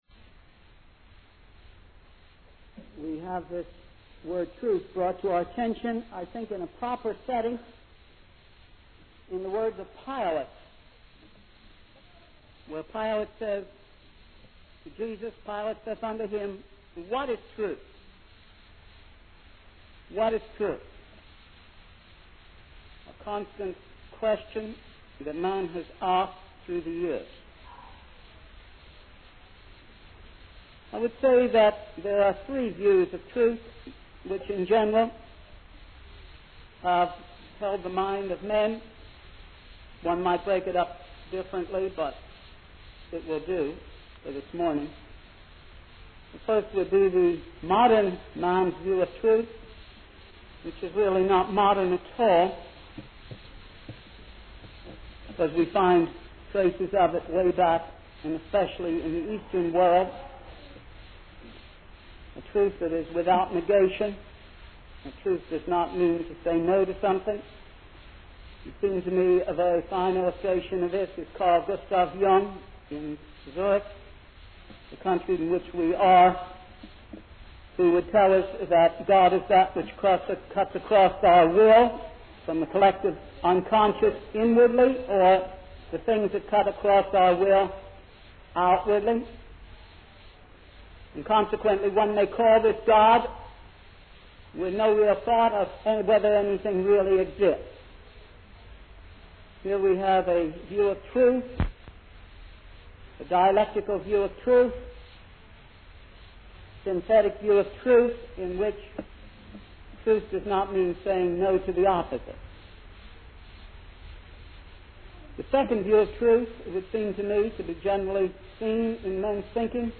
In this sermon, the preacher emphasizes the importance of acting upon the knowledge of the truth in order to experience peace. He emphasizes that God wants all people to be saved and has provided enough evidence for them to come to the knowledge of the truth. The preacher also highlights the role of believers in sharing the truth with others, citing John 17 as evidence that people become Christians through the testimony of God's people.